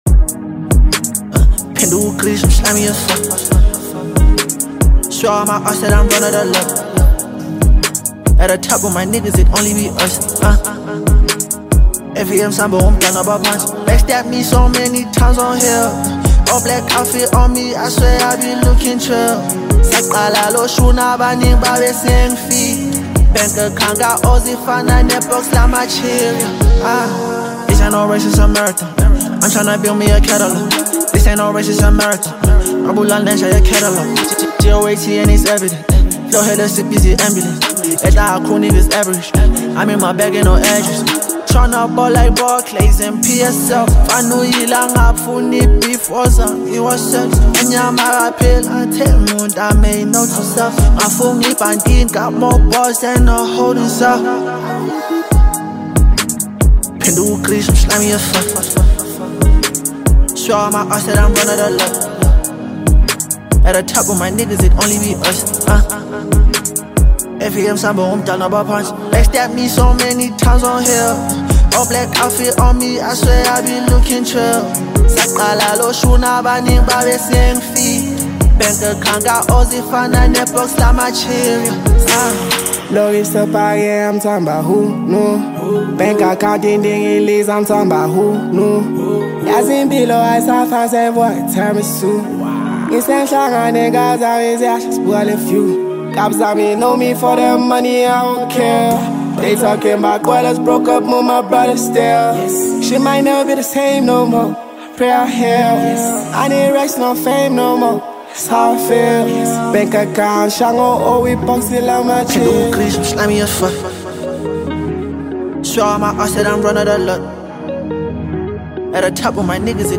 Hip Hop 0